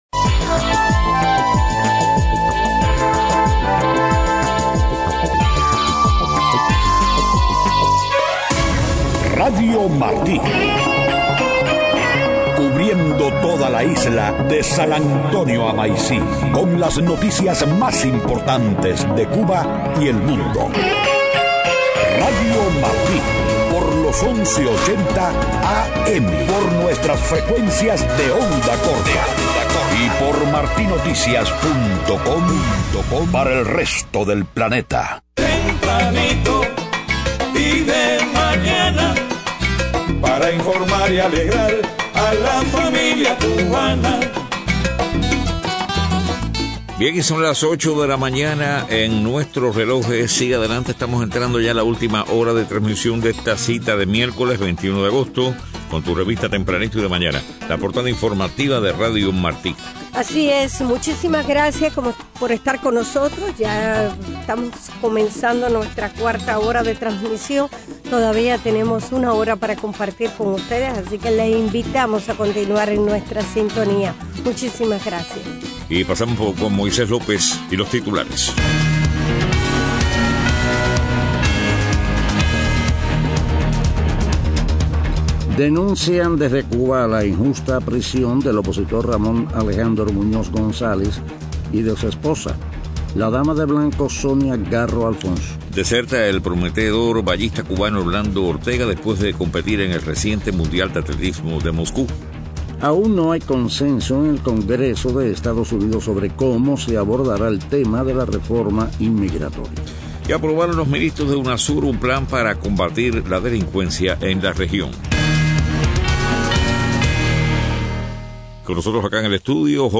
Deportes.